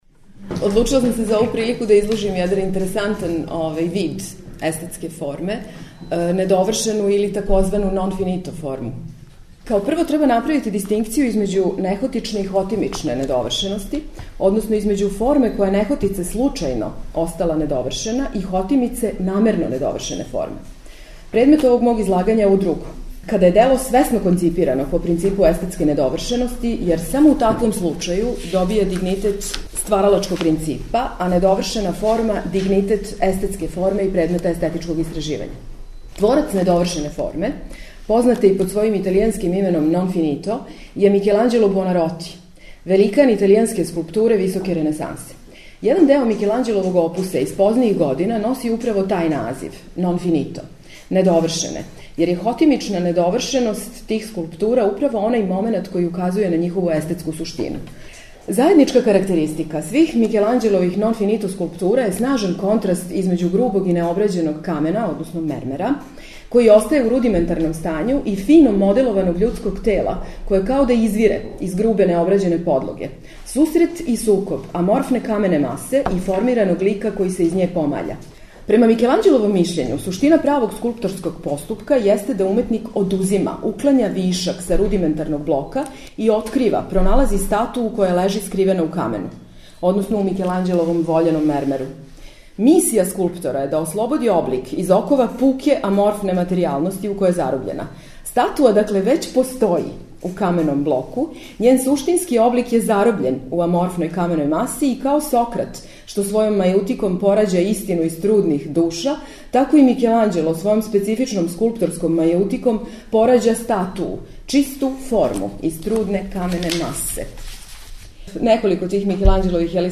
Научни скупови
У циклусу ПРОБЛЕМ ФОРМЕ четвртком и петком емитујемо снимке са истоименог научног скупа који је крајем прошле године организовало Естетичко друштво Србије из Београда.